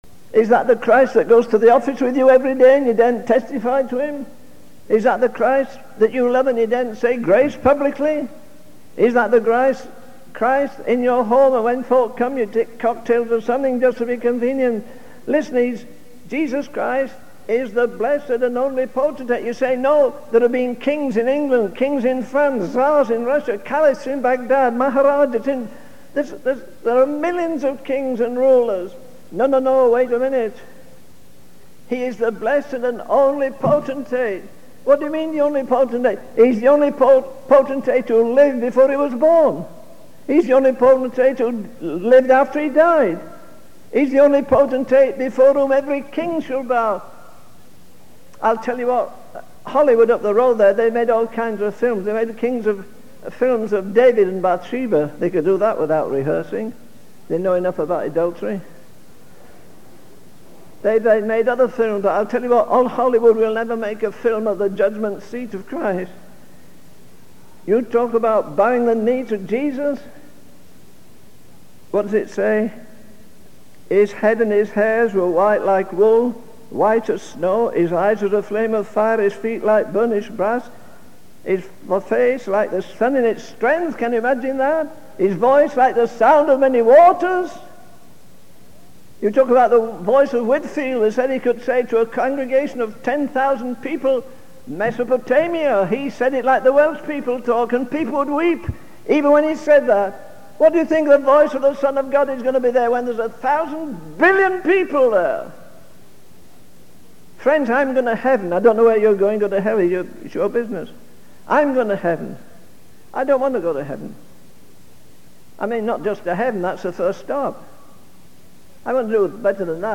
In this sermon, the preacher emphasizes the importance of true worship and warns against engaging in empty rituals. He highlights the power of the Holy Spirit and recounts a personal experience of witnessing a woman who was truly blessed by the presence of the Holy Ghost. The preacher then shifts to discussing the impending judgment that every person will face before God.